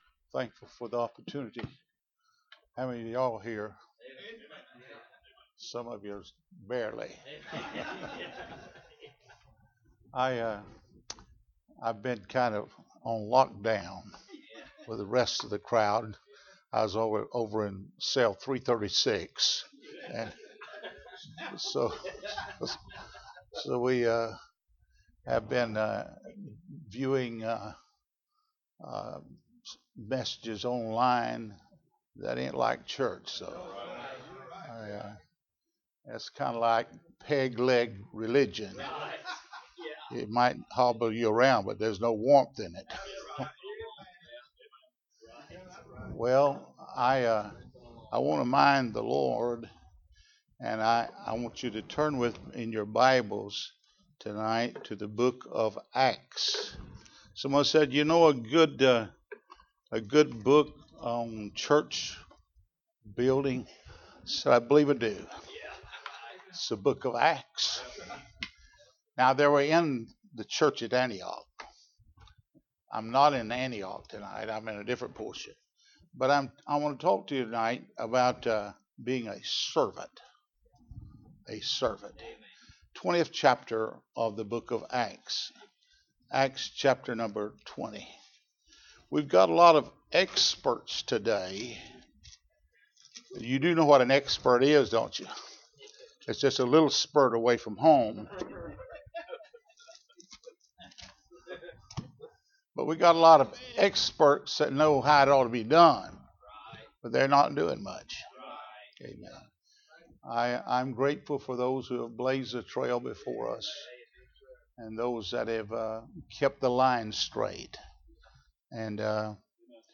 19-21 Service Type: Mission Conference Bible Text